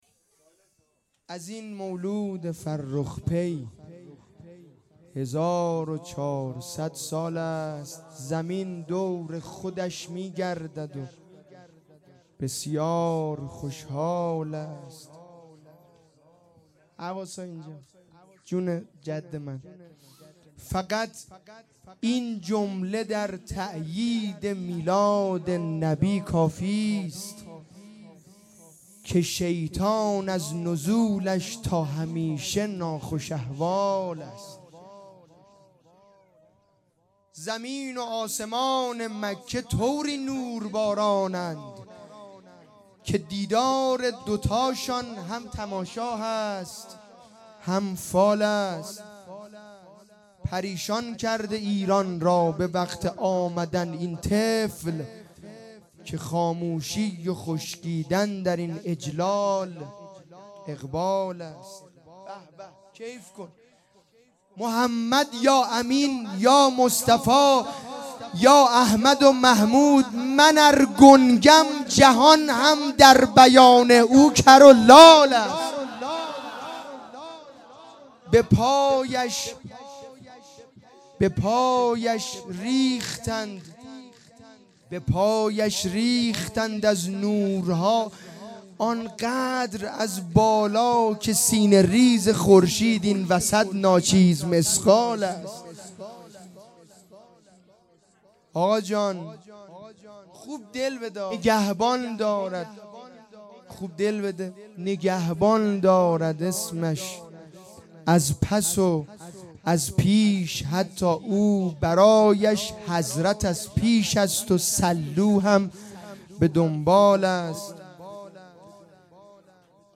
جشن ولادت پیامبر اکرم (ص) و امام صادق علیه السلام